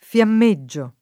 vai all'elenco alfabetico delle voci ingrandisci il carattere 100% rimpicciolisci il carattere stampa invia tramite posta elettronica codividi su Facebook fiammeggiare v.; fiammeggio [ f L amm %JJ o ], ‑gi — fut. fiammeggerò [ f L amme JJ er 0+ ] — cfr. flamboyant